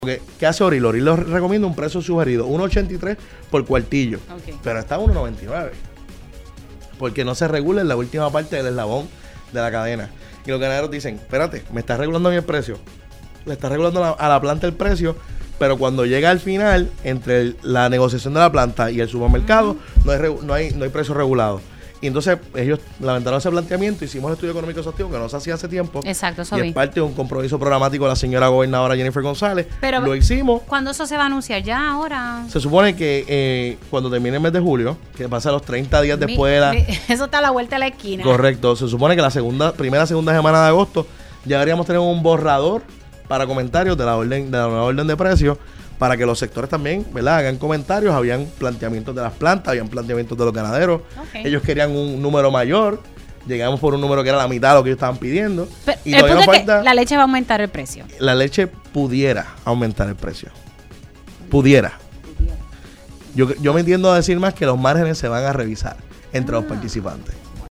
El secretario de Agricultura, Josué Rivera reveló en Pega’os en la Mañana que está en el proceso final la venta de la Suiza Dairy a una empresa local.